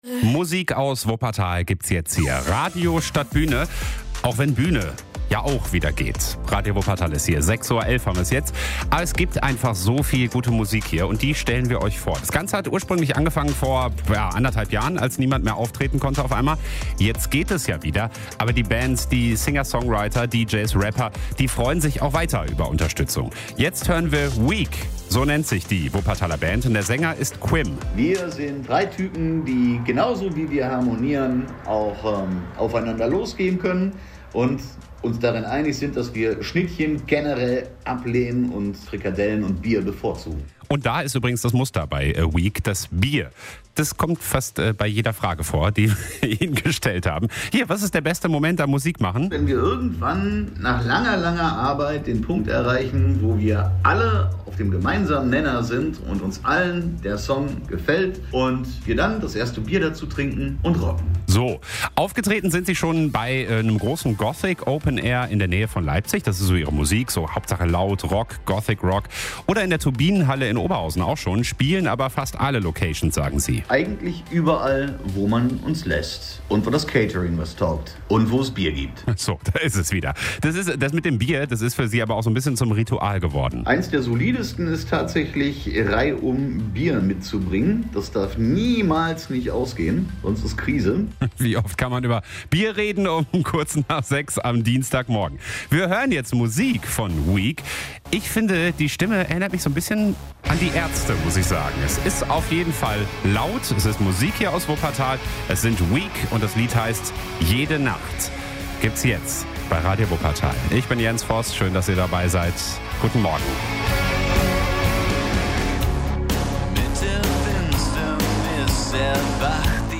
Goth-Rock - mehr davon: Homepage